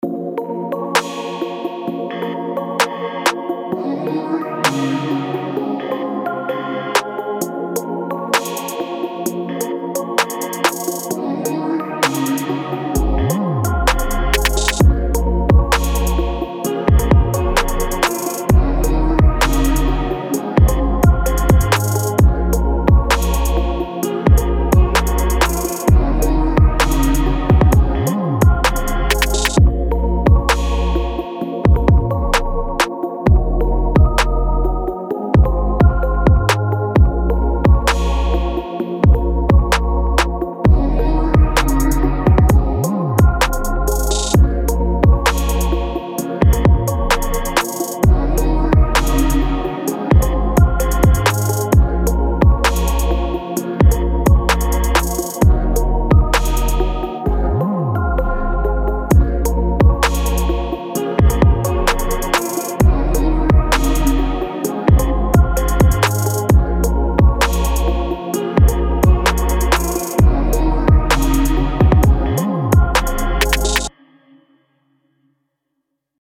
Hip-Hop, Trap, R&B, Pop or even cinematic scoring.